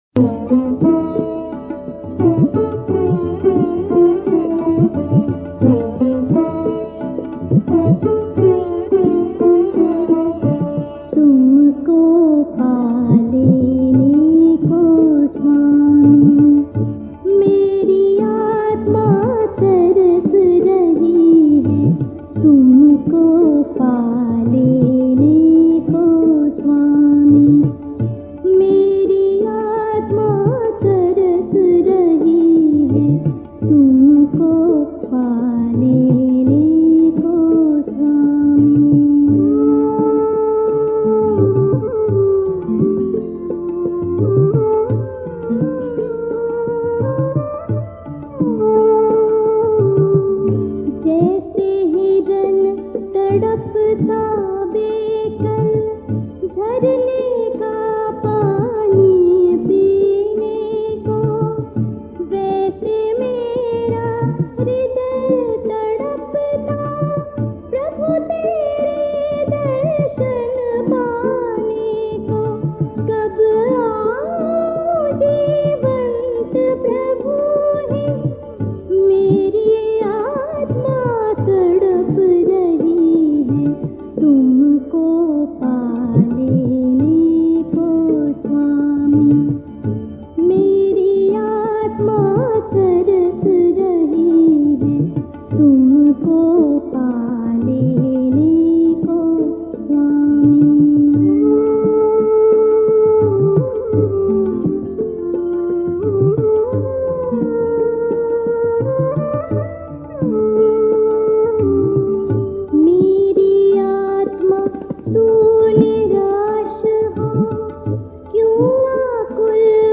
Hindi Hymns